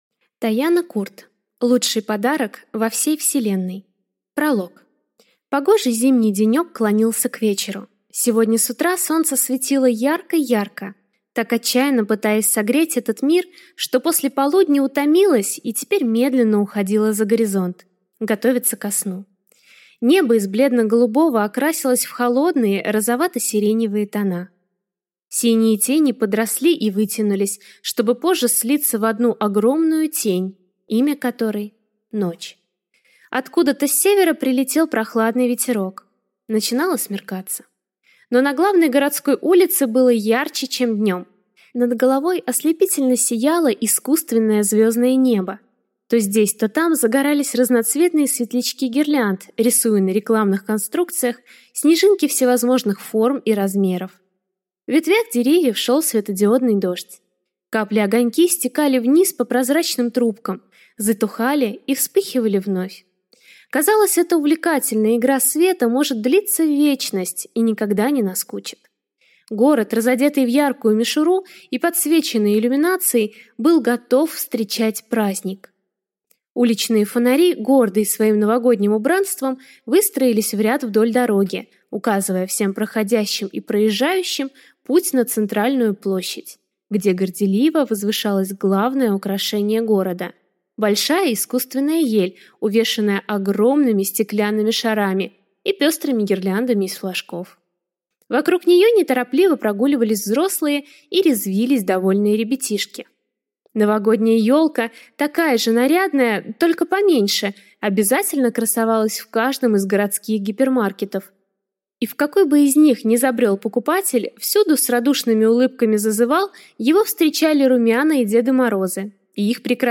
Аудиокнига Лучший подарок во всей Вселенной | Библиотека аудиокниг